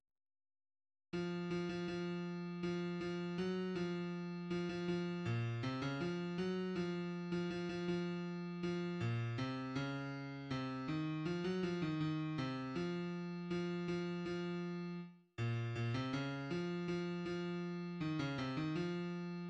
{ \clef bass \tempo 4=80 \key des \major \time 2/4 \set Score.currentBarNumber = #1 \bar "" r4 r8 f8 f16 f16 f4 f8 f ges f4 f16 f f8 bes, c16 des f8 ges f8. f16 f16 f16 f4 f8 bes, c des4 c8 ees f16 ges f ees ees8 c f4 f8 f f4 r8 bes, bes,16 c des8 f f f4 ees16 des c ees f4 } \addlyrics {\set fontSize = #-2 doggy doogy } \midi{}